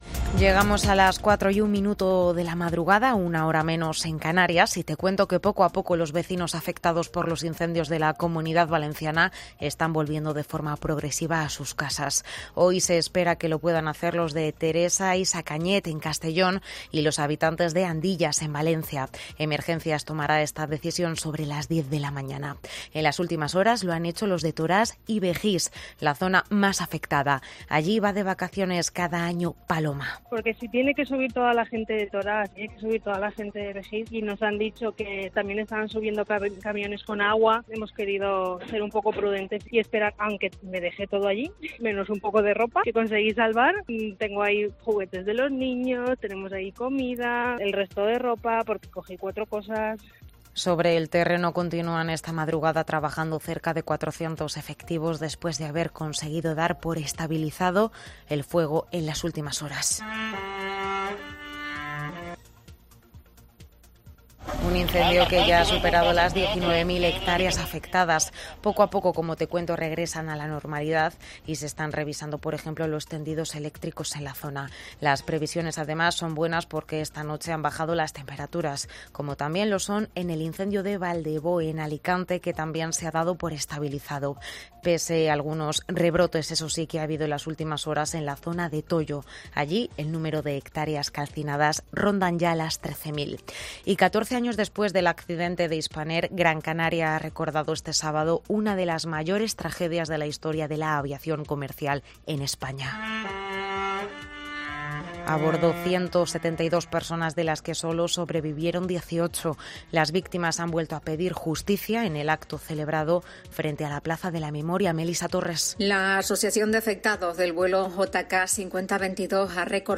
AUDIO: Boletín de noticias de COPE del 21 de agosto de 2022 a las 04.00 horas